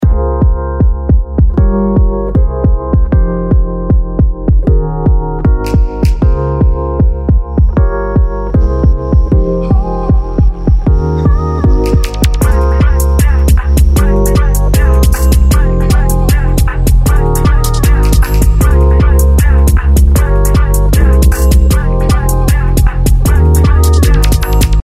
ここで筆者のトラックにサブベースを足し、プリセット「Definitive Bass」を見ていこうと思います。
タイトル通り、ベースがマジで決まります。
コンプにビットクラッシュ系、オーバードライブ系の合わせ技なのですが、かけすぎると若干ボリュームが上がるものの、サウンドが破綻することなくバッチリ出てきます。